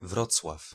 Wrocław (Polish: [ˈvrɔt͡swaf]